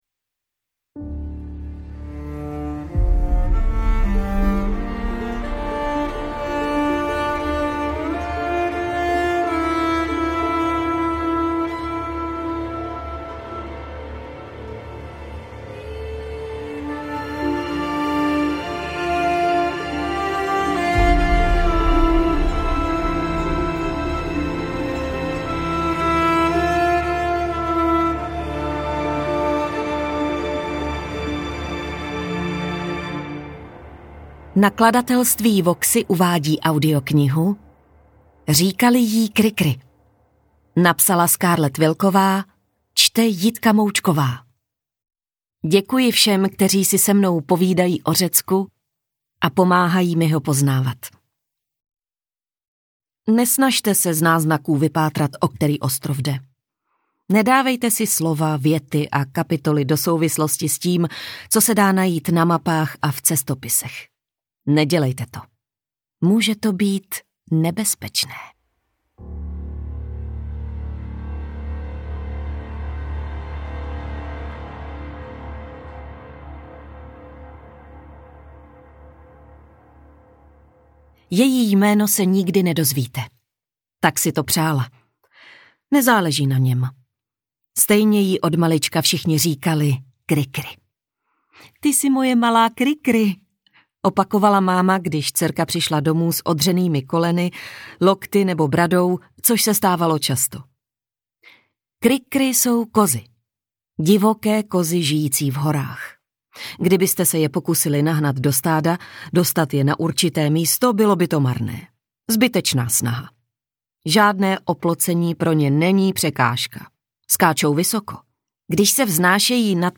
Interpret:  Jitka Moučková
AudioKniha ke stažení, 50 x mp3, délka 9 hod. 59 min., velikost 546,0 MB, česky